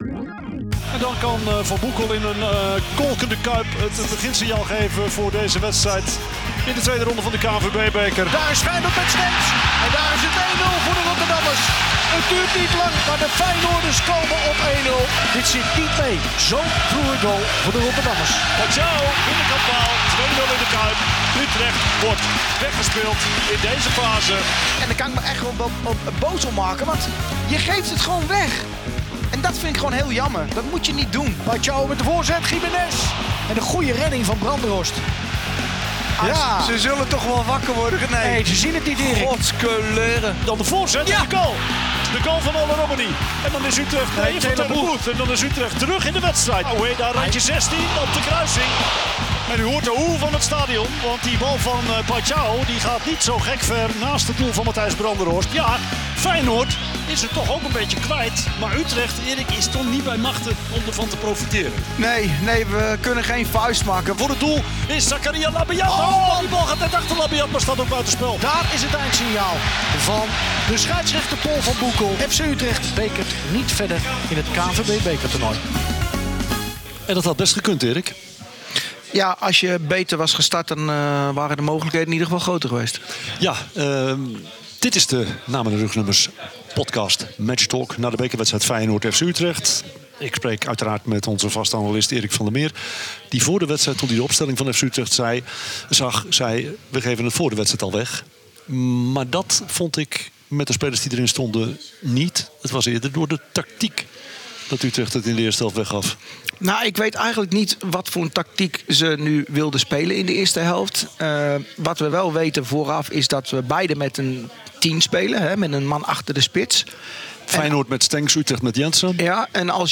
In de catacombe van de Rotterdamse Kuip